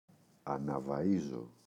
αναβαΐζω [anavaꞋizo]